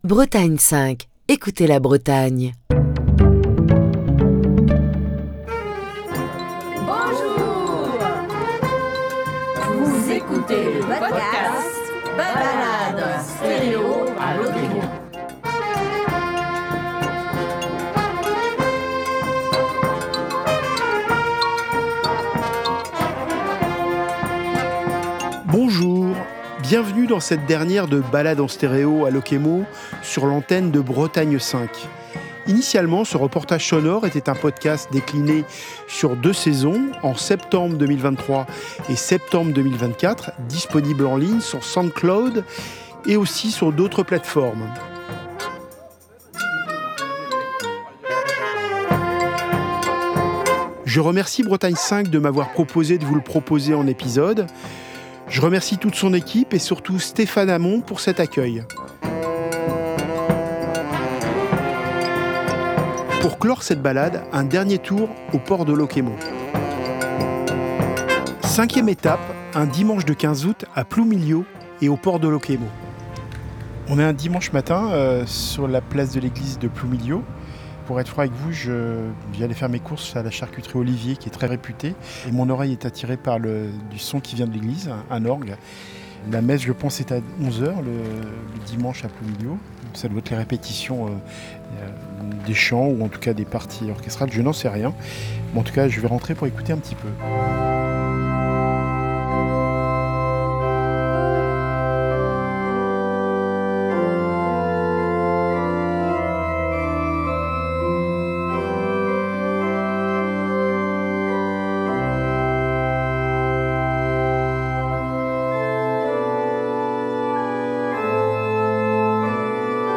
Pour la dernière de cette Balade en stéréo à Lokémo, nous partons faire un tour à Ploumilliau, pour écouter les orgues de Lankou.